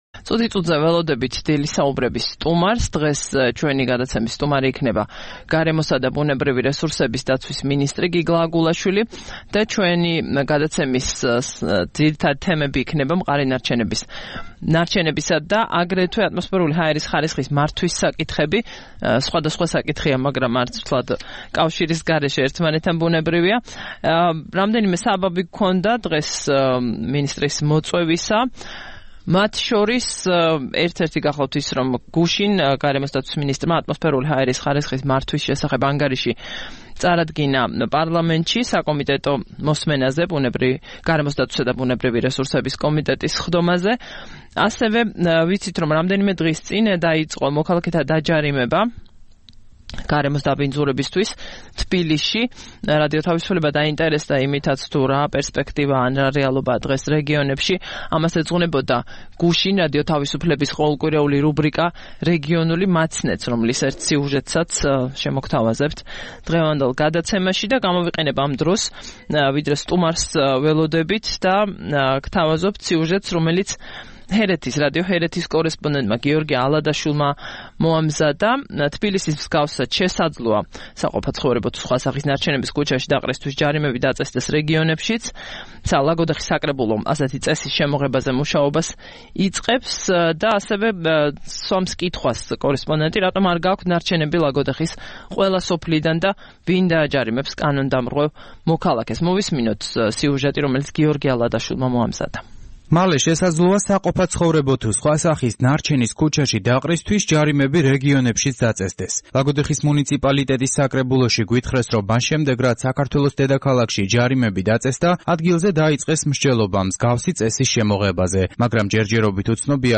26 აპრილს რადიო თავისუფლების "დილის საუბრების" სტუმარი იყო გიგლა აგულაშვილი, გარემოსა და ბუნებრივი რესურსების დაცვის მინისტრი.